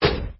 铁匠-长柄武器音效.mp3